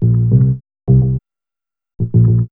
0508L B-LOOP.wav